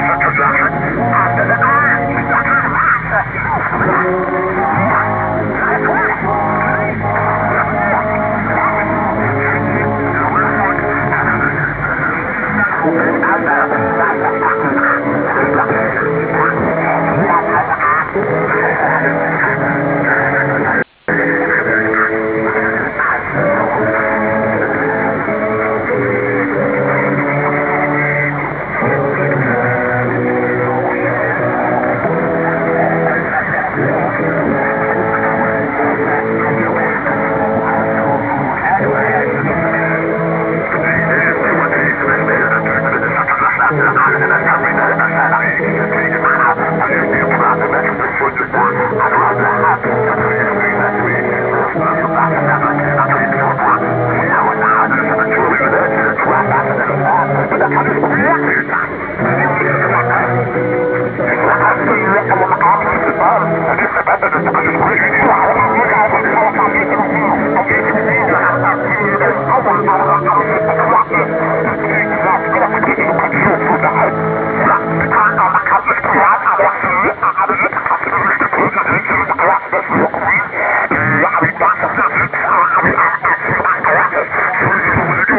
Working my way through wav files from a recent DXpedition to coastal Washington and Oregon I found this sign/off of Tavalu on 621 kHz from Sans Souci [between Florence and Yachats, OR] on July 30 at 1000utc:
It’s 2:45 long with mention of Tuvalu at 1:10 and a couple of ‘national anthems.’
Tough copy next to 620.  Had the notch on and really ‘tailored’ the passband.